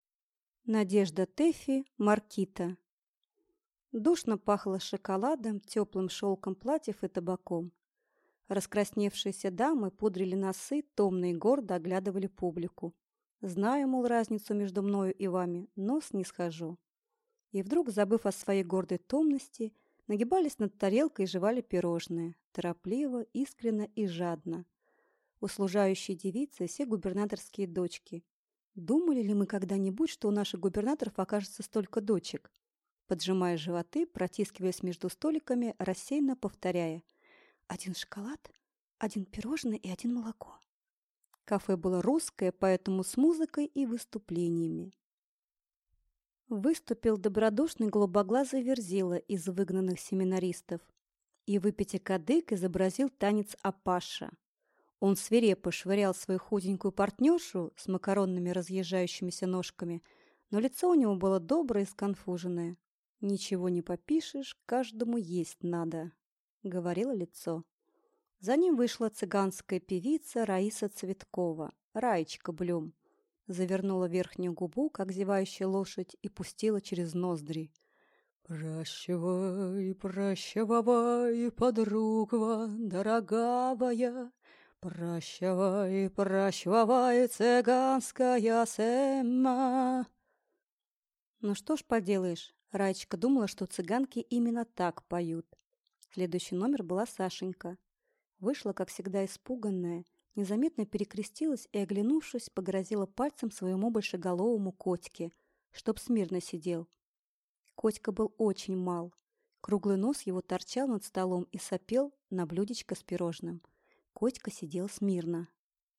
Аудиокнига Маркита | Библиотека аудиокниг